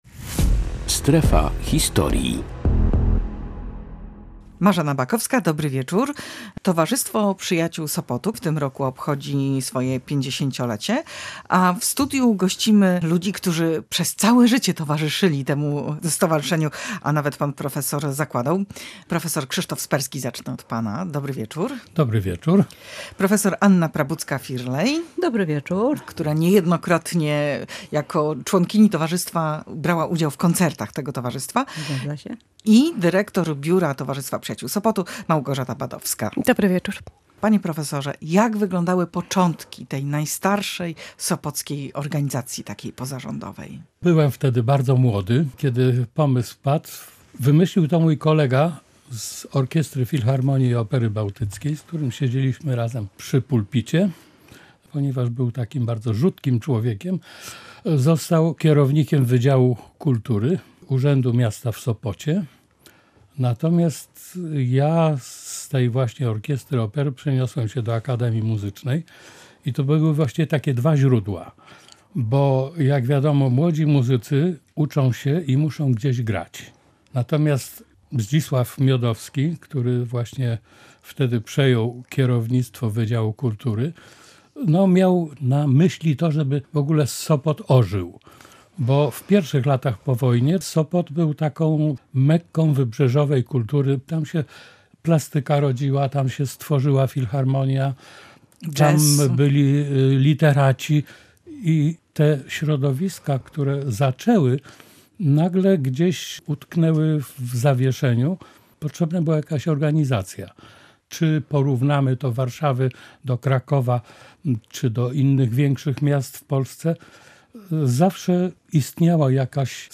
W audycji oboje opowiedzieli o muzycznych czwartkach i o przeszłości Towarzystwa Przyjaciół Sopotu, które świętuje swoje 50-lecie. W studiu gościła także